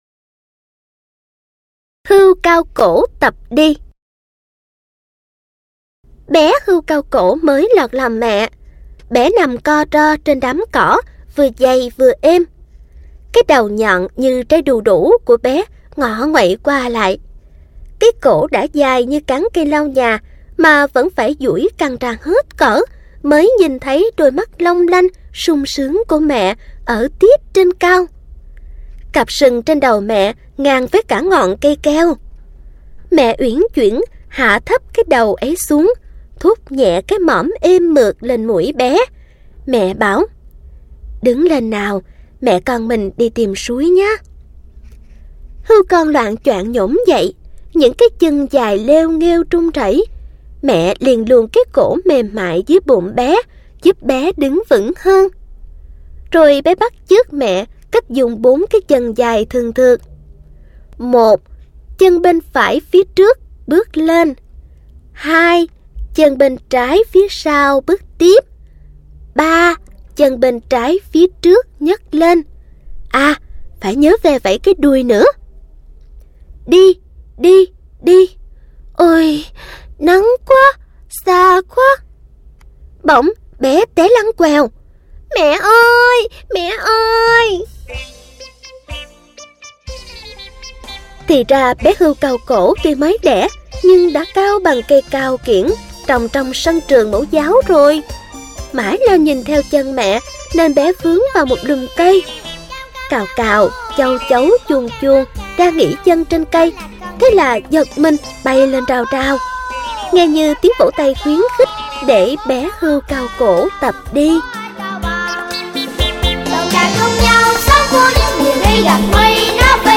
Sách nói | Xóm Đồ Chơi P30